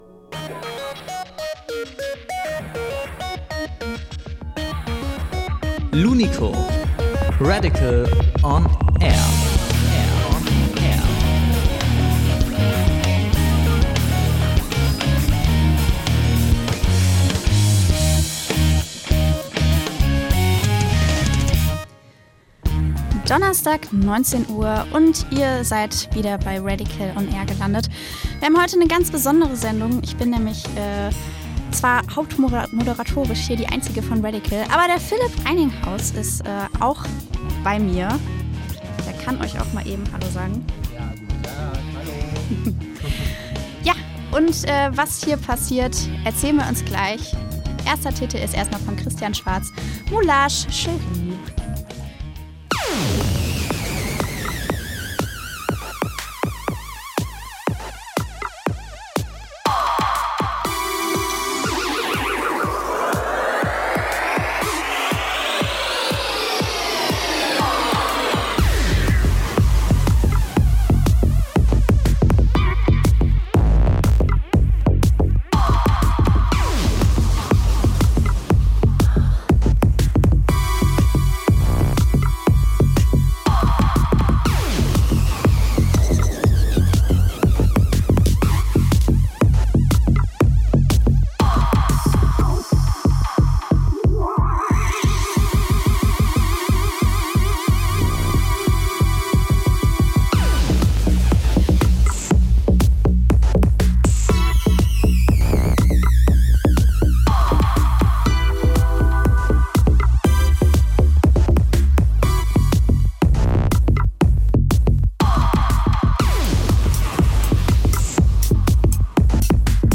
live im Studio
Elektro-Special